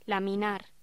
Título Locución: Laminar